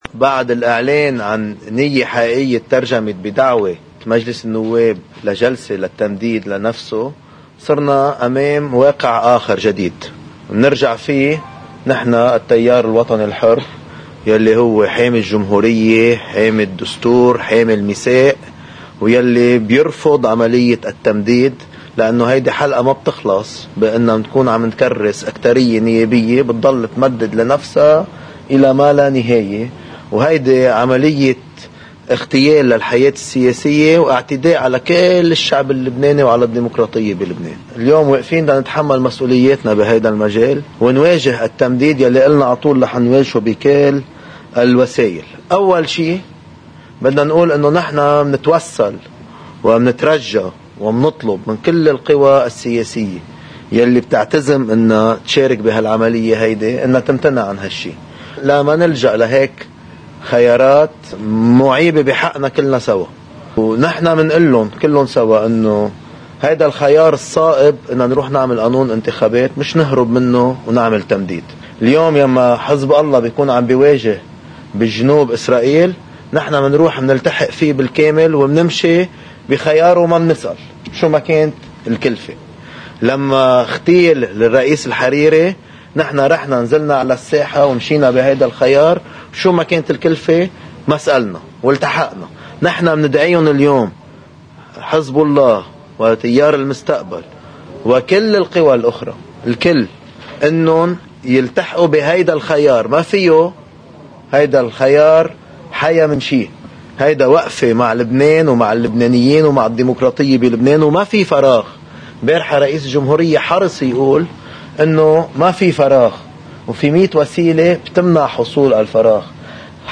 مقتطف من حديث رئيس التيار الوطني الحر جبران باسيل، اثر اجتماع تكتّل التغيير والإصلاح: